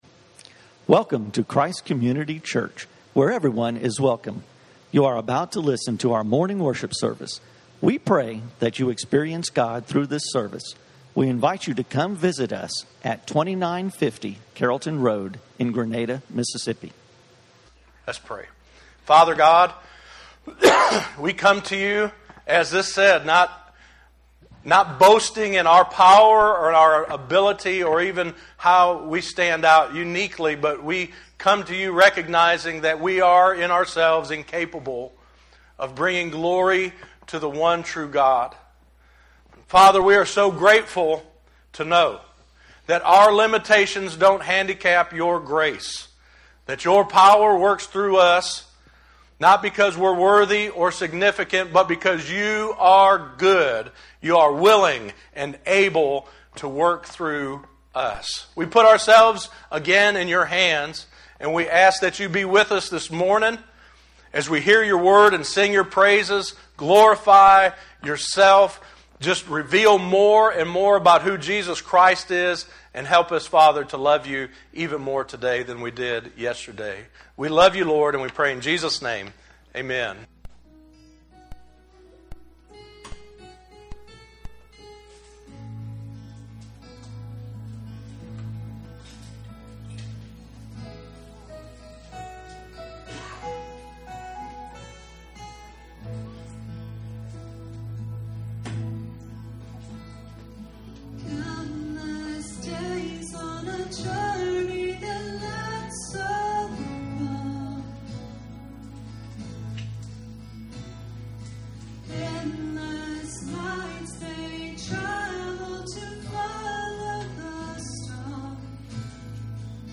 JESUS Is The Perfect Pastor - Messages from Christ Community Church.